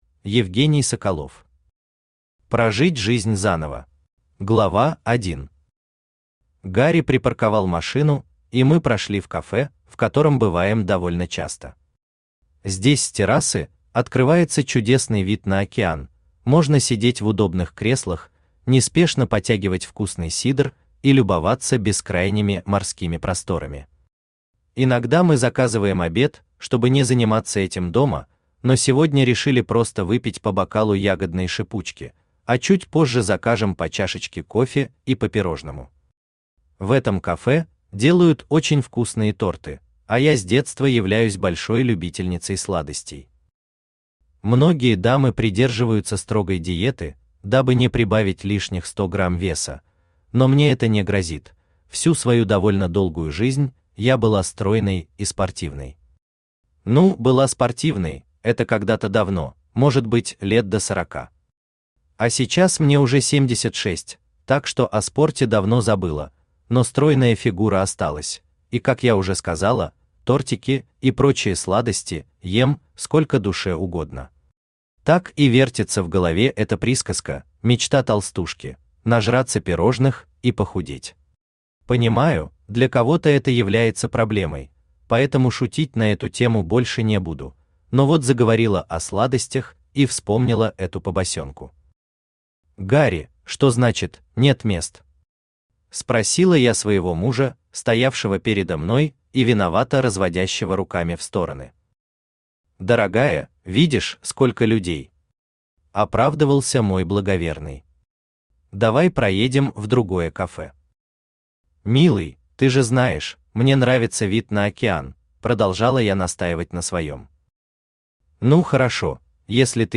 Аудиокнига Прожить жизнь заново | Библиотека аудиокниг
Aудиокнига Прожить жизнь заново Автор Евгений Владимирович Соколов Читает аудиокнигу Авточтец ЛитРес.